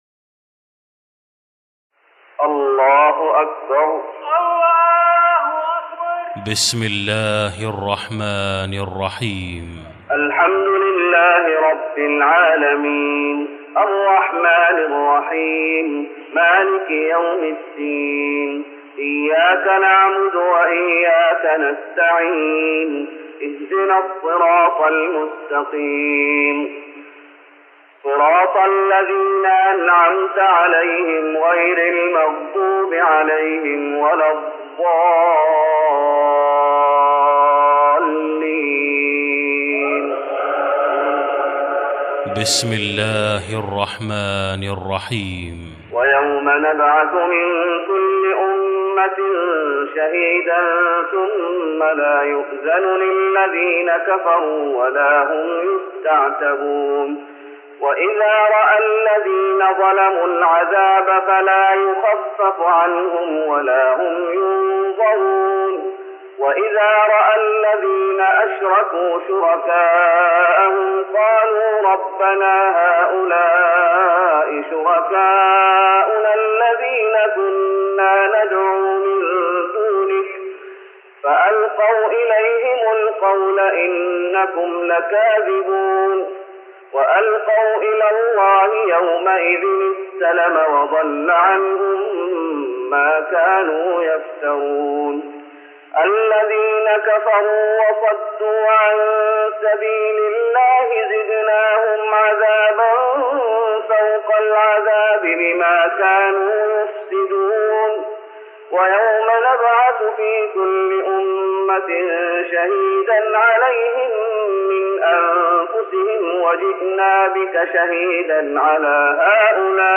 تراويح رمضان 1414هـ من سورتي النحل 84 الى الإسراء 22 Taraweeh Ramadan 1414H from Surah An-Nahl and Al-Israa > تراويح الشيخ محمد أيوب بالنبوي 1414 🕌 > التراويح - تلاوات الحرمين